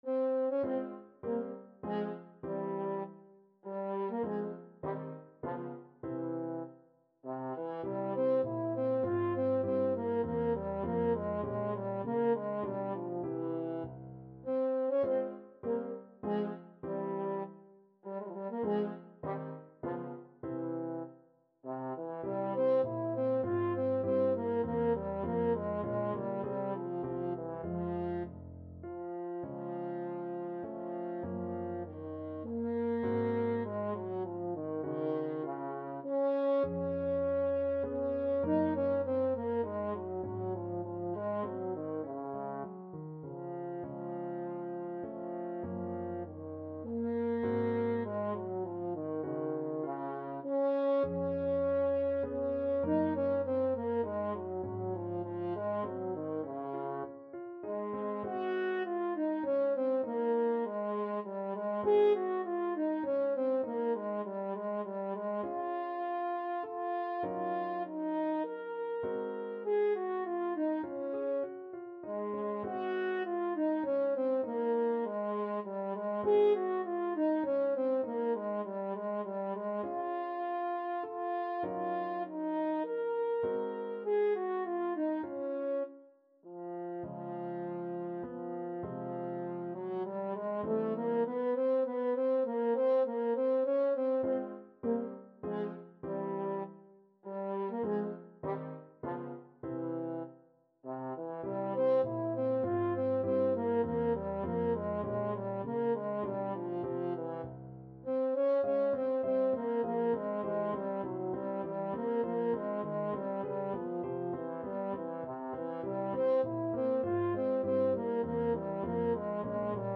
3/4 (View more 3/4 Music)
Allegretto
Classical (View more Classical French Horn Music)